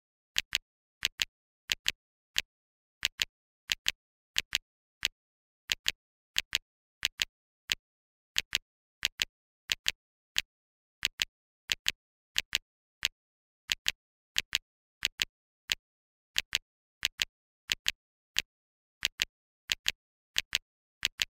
标签： 90 bpm Rap Loops Drum Loops 3.59 MB wav Key : Unknown
声道立体声